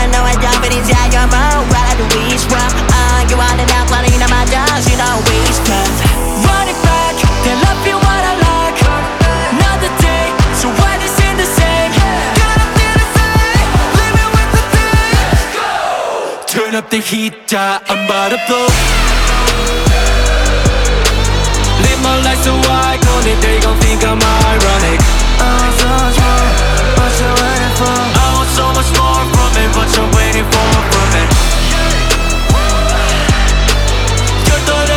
Скачать припев
K-Pop Pop
2025-08-01 Жанр: Поп музыка Длительность